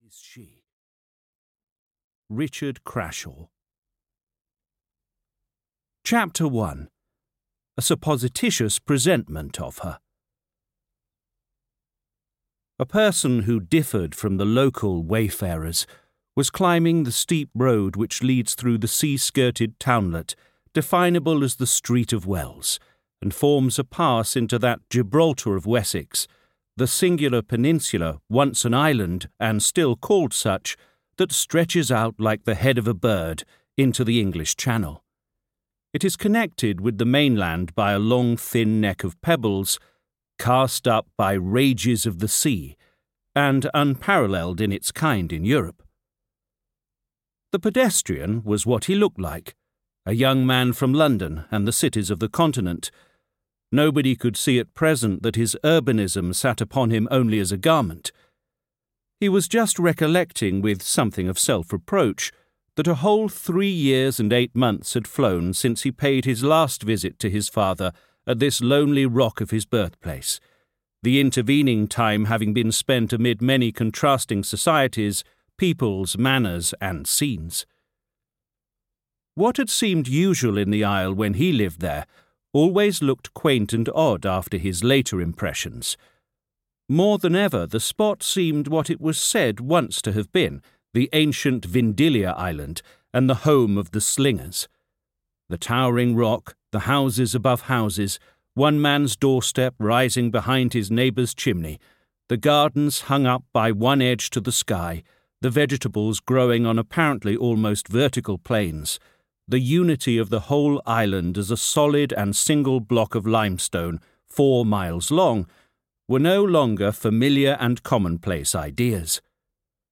The Well-Beloved (EN) audiokniha
Ukázka z knihy